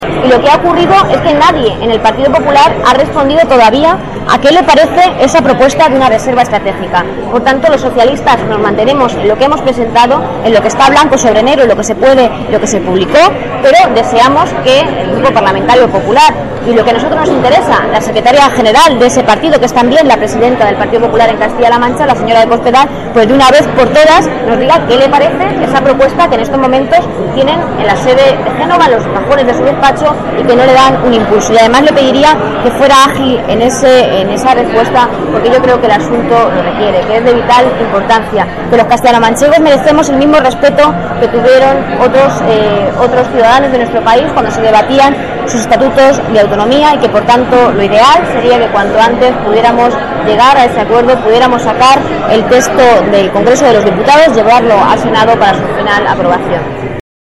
Rodríguez atiende a los medios de comunicación.
La secretaria de Sociedad de la Información y portavoz del Gobierno regional, Isabel Rodríguez, ha criticado hoy en Almansa el silencio preocupante que mantienen los dirigentes del PP sobre el futuro de nuestro Estatuto de Autonomía.
Las declaraciones de Rodríguez se produjeron en el marco de las Conferencias Políticas 2010 de Almansa que, bajo el título «Almansa + PSOE, Trabajando por el futuro», tratarán de reflexionar acerca del presente y futuro de la localidad.